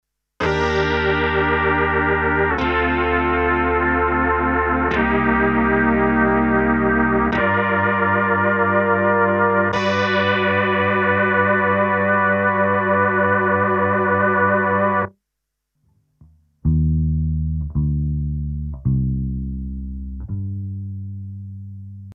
min 7/maj 7 chord pad – Future Impact Program Database
50+min-maj+7+saw+pad_ok.mp3